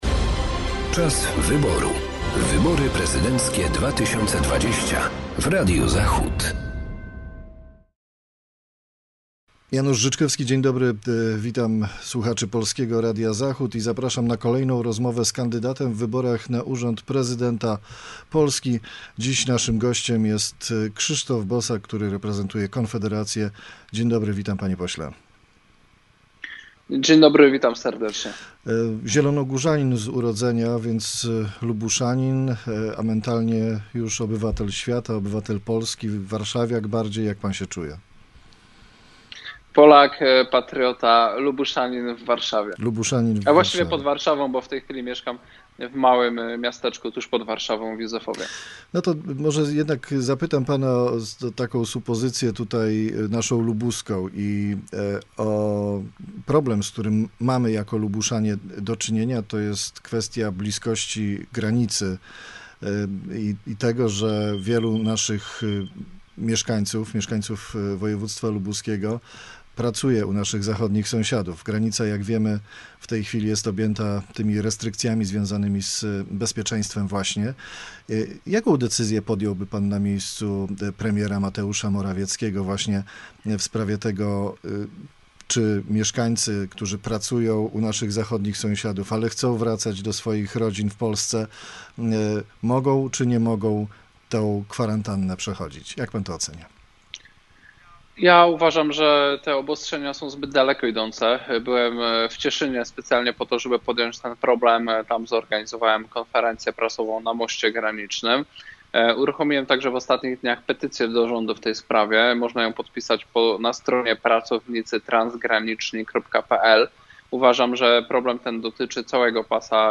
studio-wyborcze-polskiego-radia-zachod-krzysztof-bosak.mp3